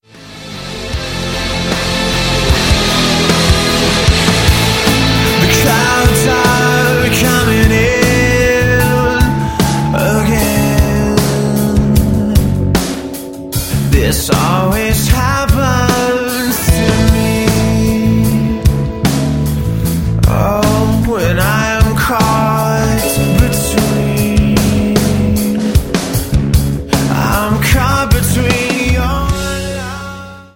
• Sachgebiet: Rock